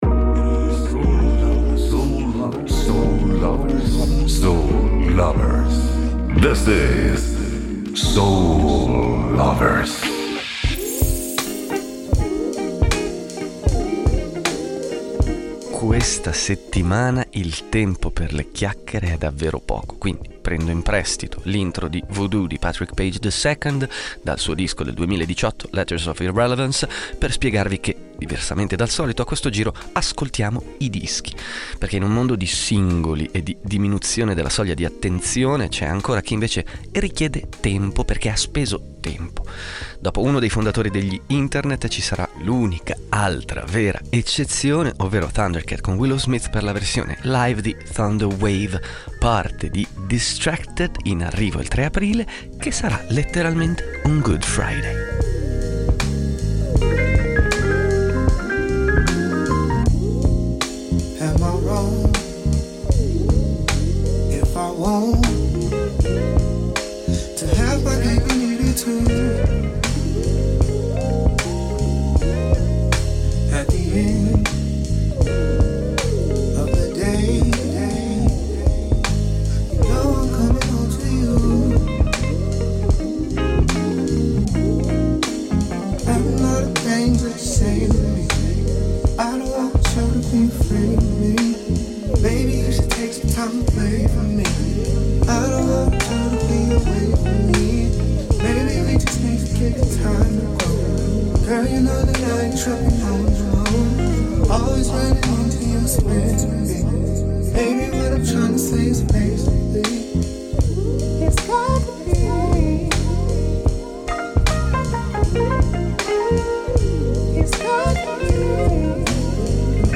Per questo motivo in questa puntata ascolteremo estesi estratti da album. Dischi veri, fatti per essere un viaggio che ha un inizio e una fine, un percorso, un’esperienza.